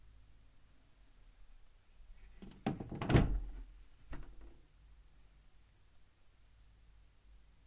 Three piece mirrored cabinet Door opening
Duration - 7 s Environment - Absorption materials, open space. Description - Plastic cabinet, mirrored doors, Clips and clatters when opening.